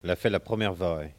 RADdO - Il a tracé le premier sillon - Document n°233477 - Locution